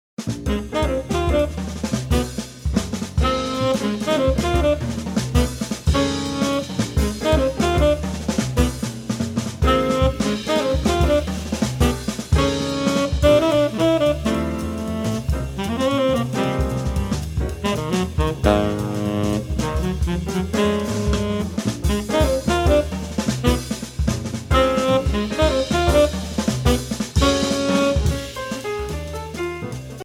Voicing: Drumset Method